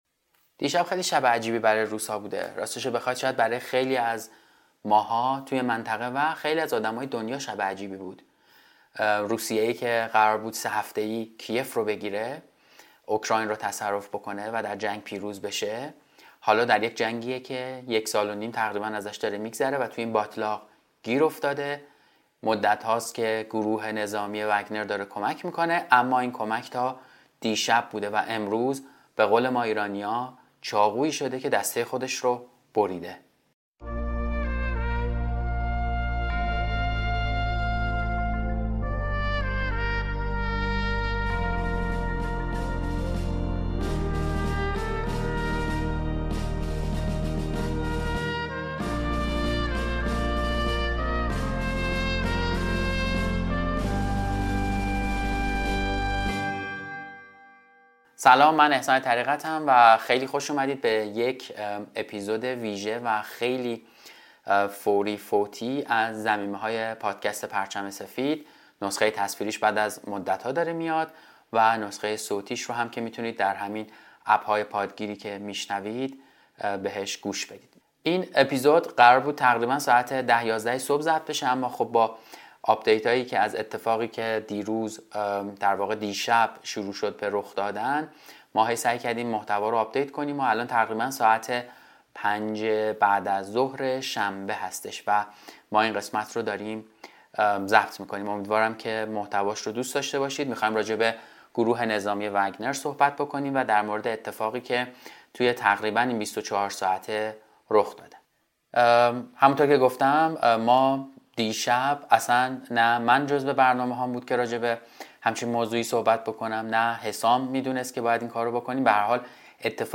موسیقی آغازین و انتهایی